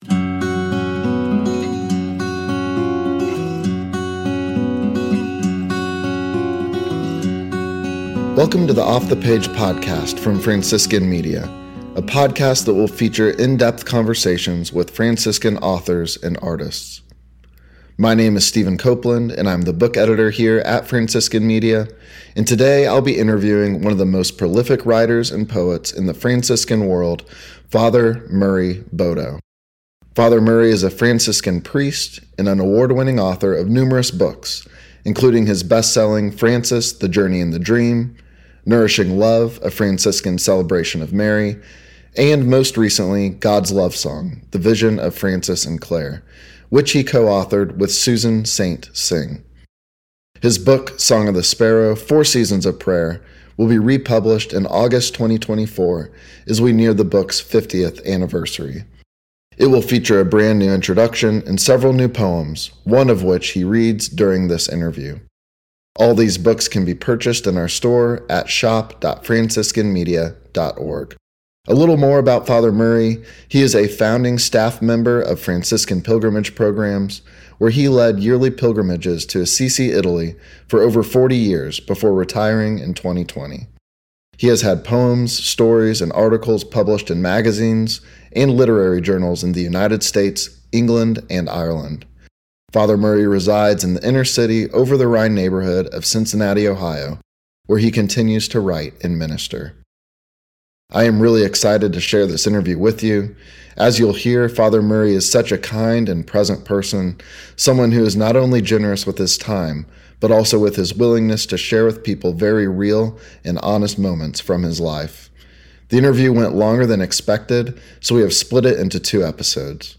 It will feature a new introduction and several new poems, one of which he reads during this two-part interview.